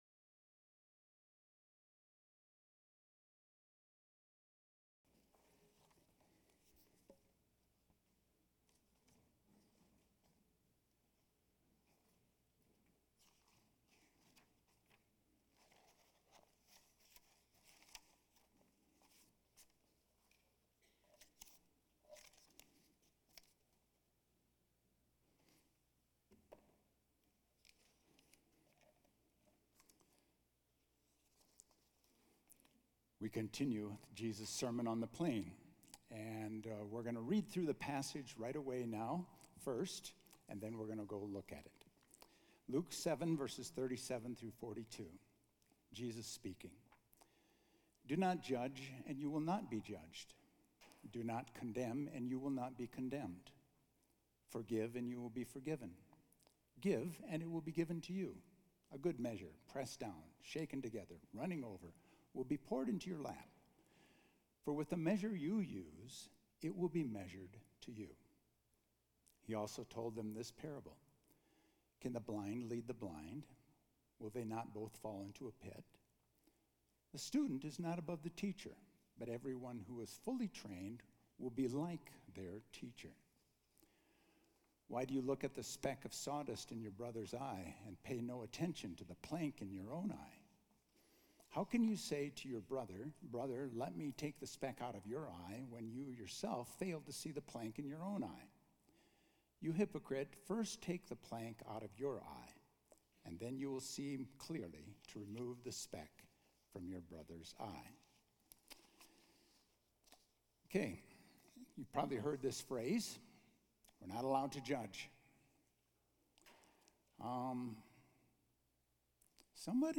A message from the series "Sermon on the Plain."